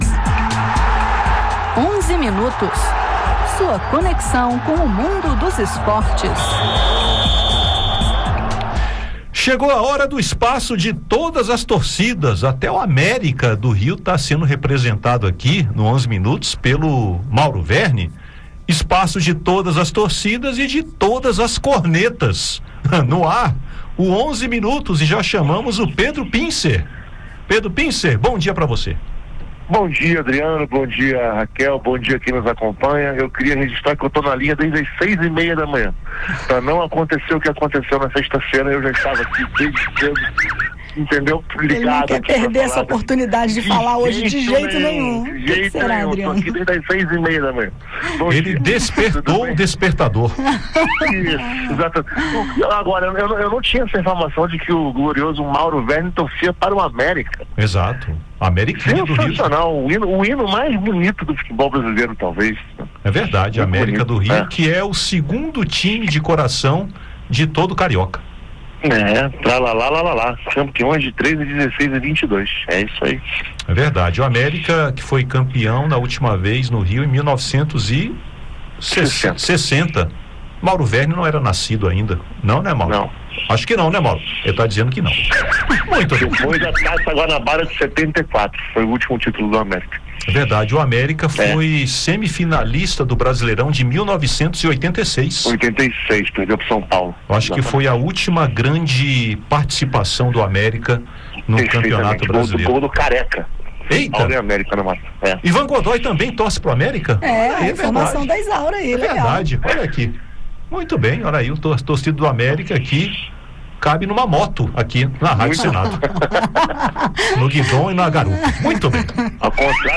Acompanhe o depoimento da senadora Leila Barros (PDT-DF) sobre o legado de Francisco e sua paixão pelo esporte e, em seguida, ouça os principais destaques do futebol.